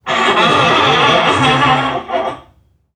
NPC_Creatures_Vocalisations_Robothead [44].wav